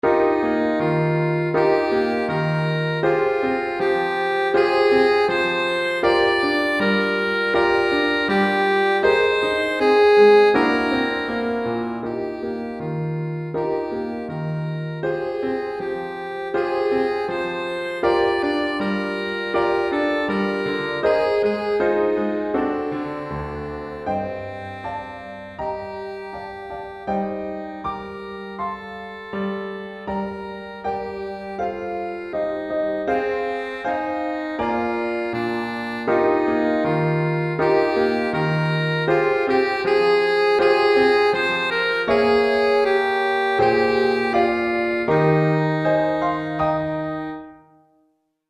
Saxophone Alto et Piano